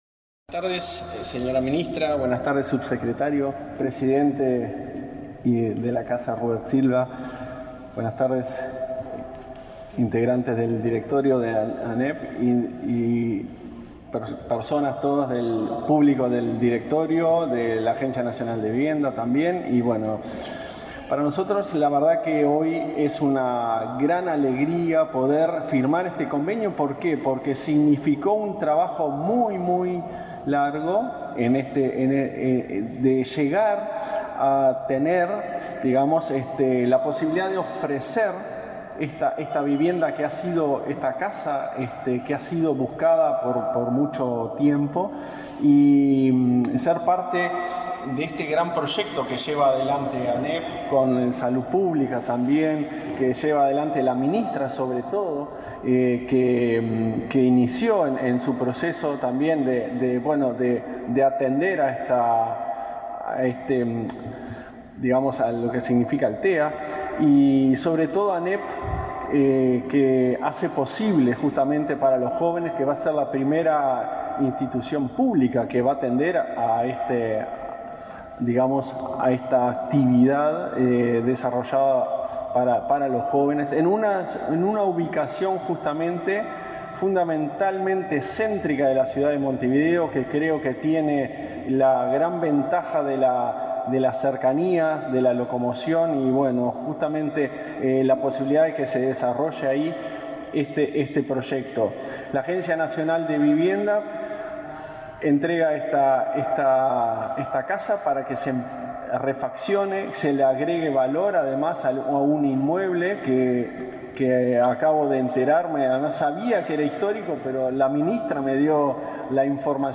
Conferencia de prensa por firma de convenio entre ANEP y ANV
El acuerdo permitirá que el centro de recursos para alumnos con trastorno del espectro autista dispongan de un local para sus alumnos. Participaron el presidente de la ANEP, Robert Silva; la titular del MVOT, Irene Moreira, y el presidente de la ANV, Klaus Mill von Metzen.